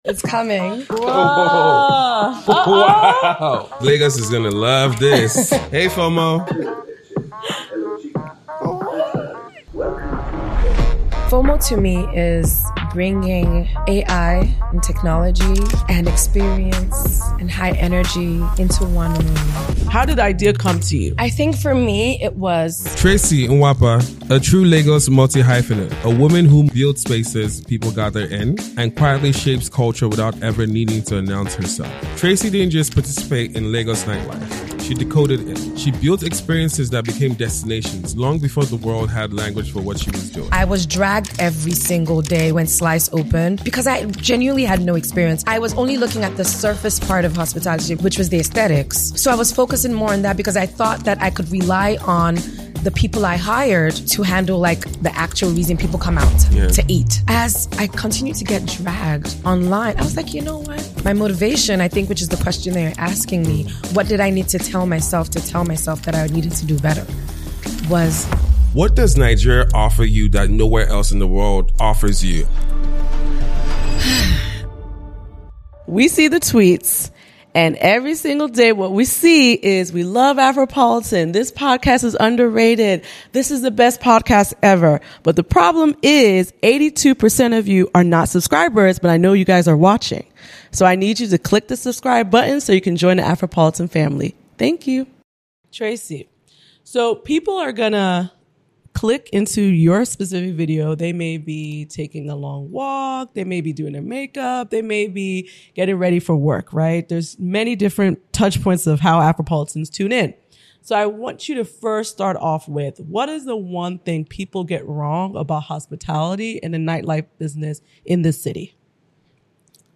This conversation goes beyond nightlife. It’s about purpose, feminine leadership in male-dominated industries, building with integrity in broken systems, and why Nigeria still feels like home despite the chaos.